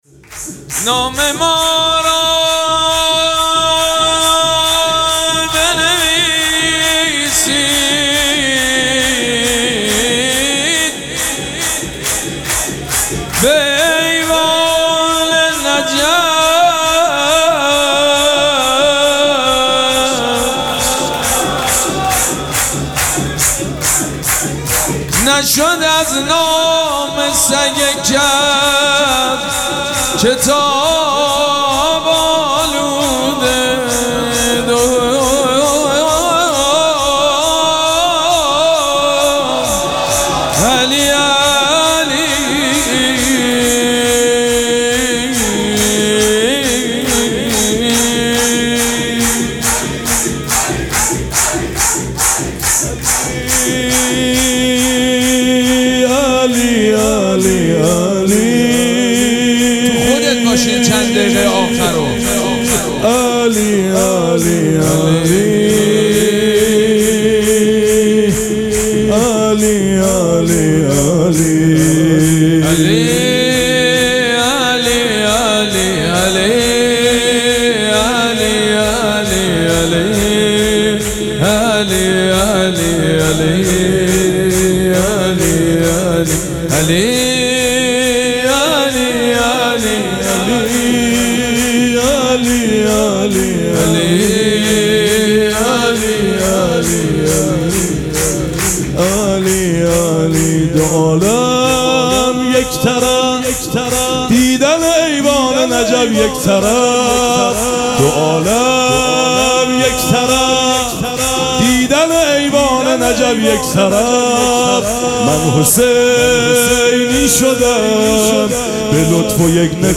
مراسم جشن ولادت حضرت زینب سلام‌الله‌علیها
سرود